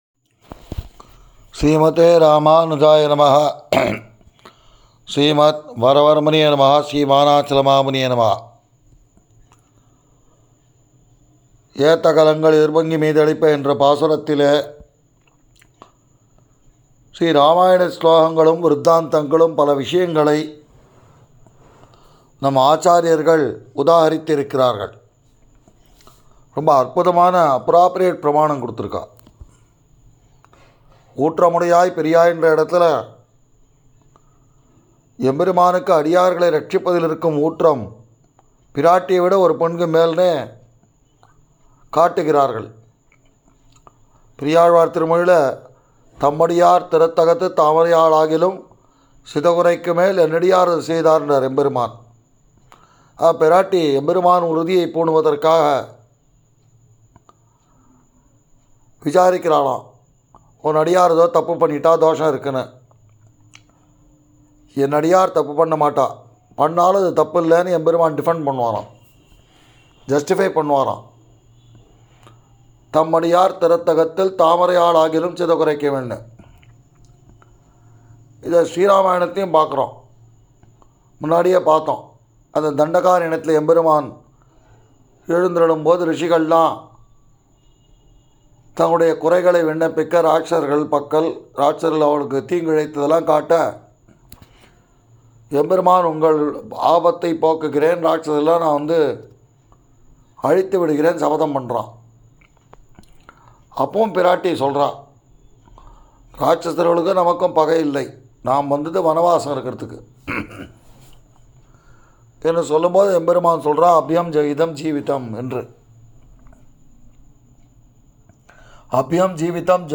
மார்கழி ௴ உபன்யாசம்